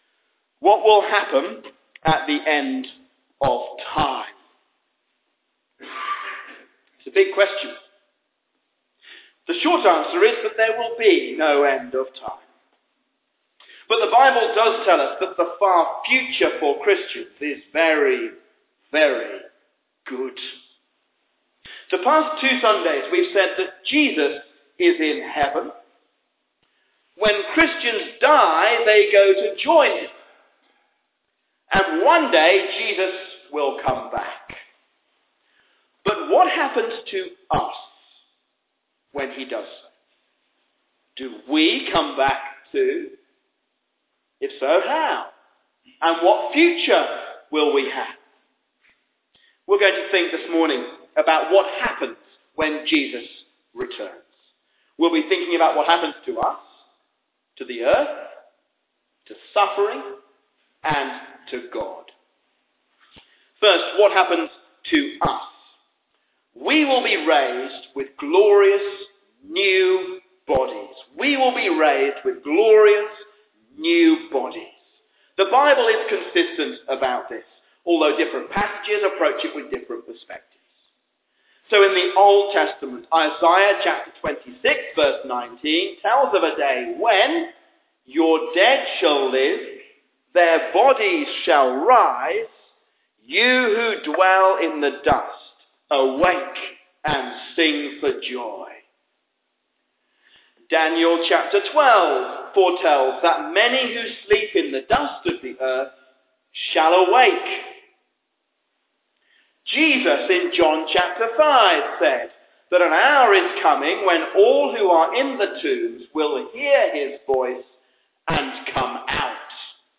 New Creation, A sermon